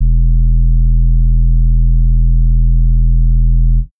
{808} ENDS.wav